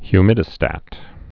(hy-mĭdĭ-stăt)